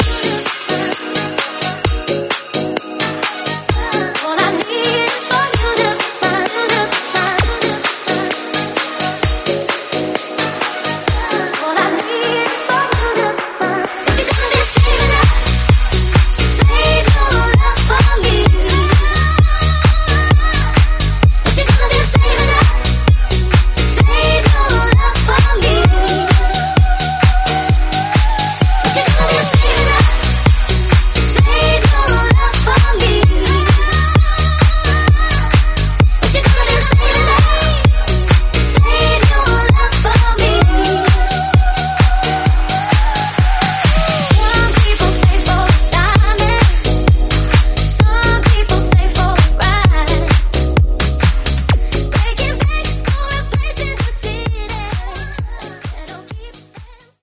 Genre: DANCE
Clean BPM: 124 Time